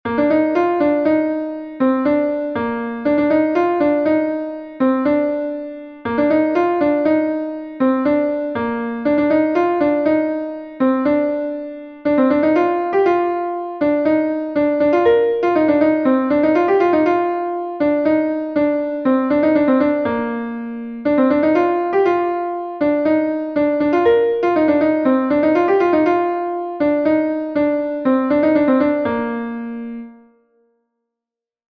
Ton Bale Saint-Tevi is a Bale from Brittany